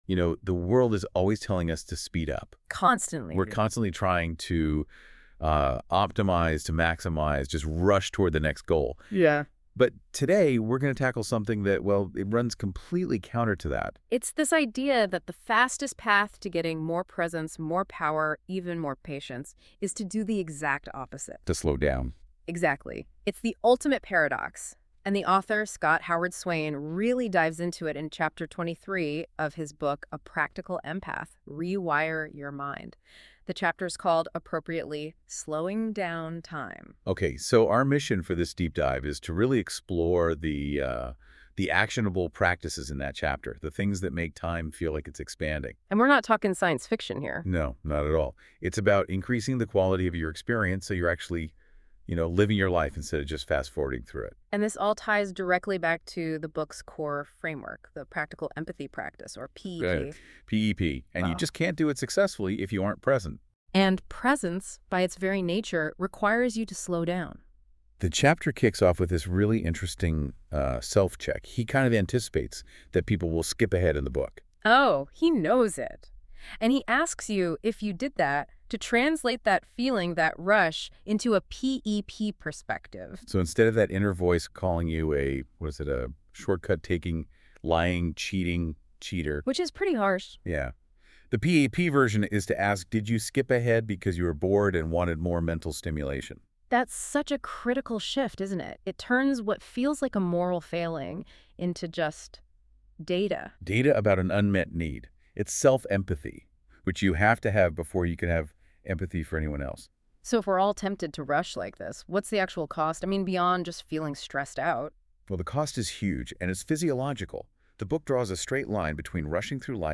AND a 10 minute 2-person talk on same: